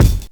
INSKICK03 -L.wav